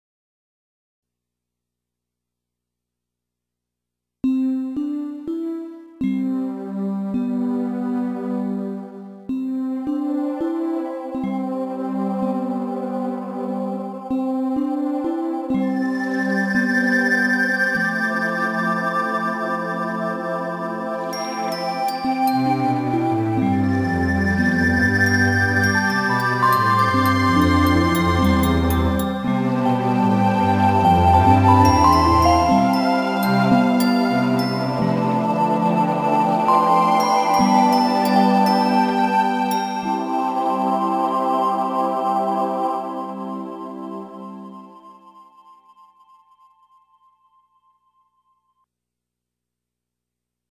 Just wanted to share the tiny firstfruits of a new recreational toy (Korg Triton LE, Digidesign Pro Tools, etc) with my dear friends out there...a couple of bars of “